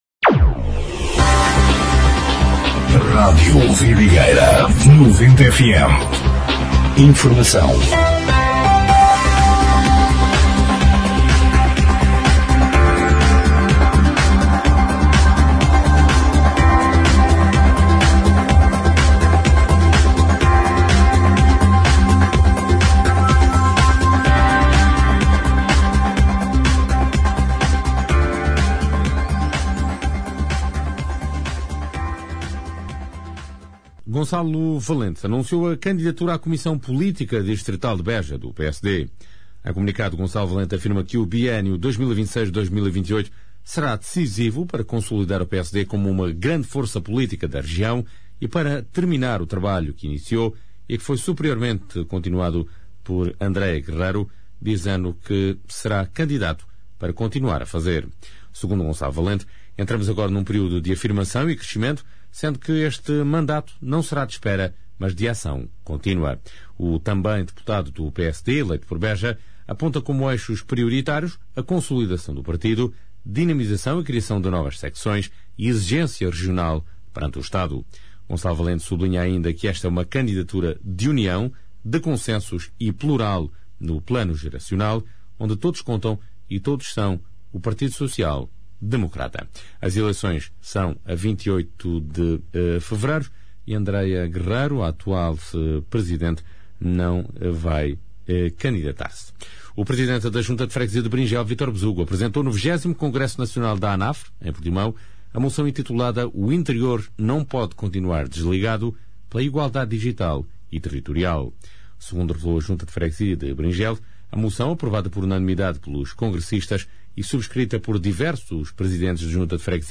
Noticiário 18/02/2026